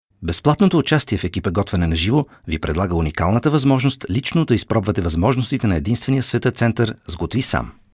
Sprecher bulgarisch für TV / Rundfunk / Industrie / Werbung.
Sprechprobe: Werbung (Muttersprache):
bulgarian voice over artist